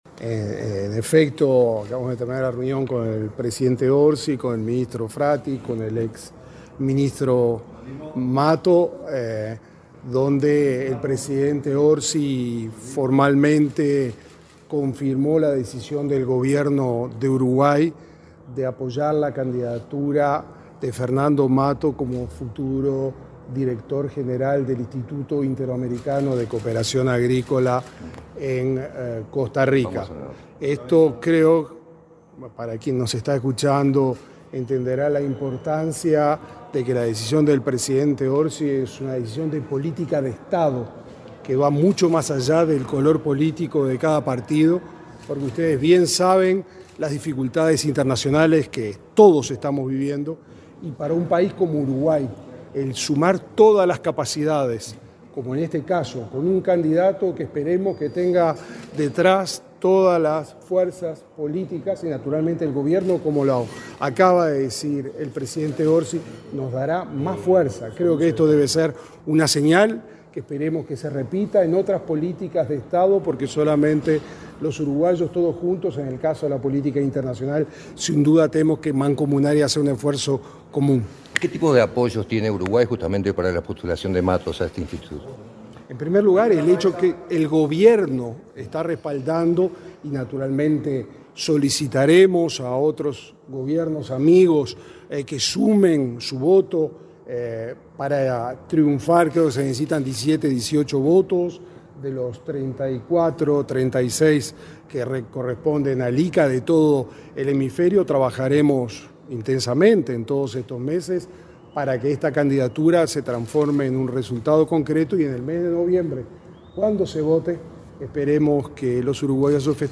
Declaraciones del canciller Mario Lubetkin, y del exministro de Ganadería, Fernando Mattos
Mattos expresó a la prensa sentirse honrado por el apoyo del primer mandatario.